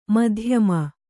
♪ madhyama